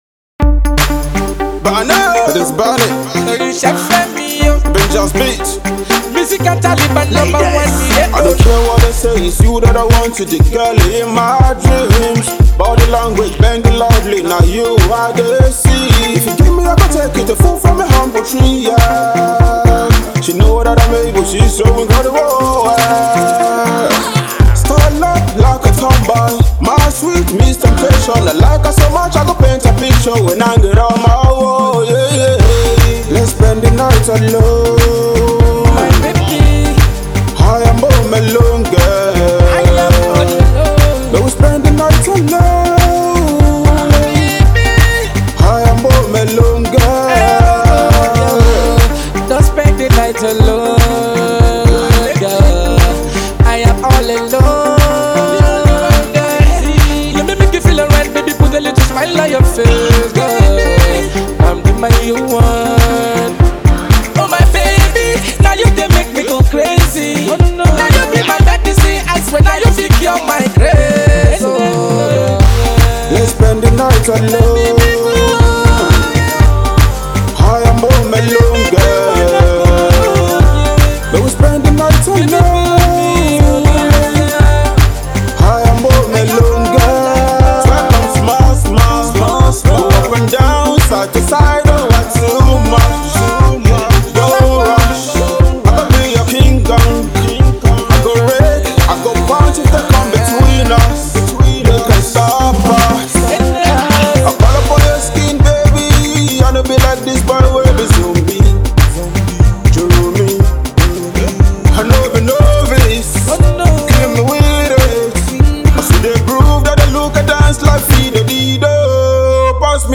collaboration tune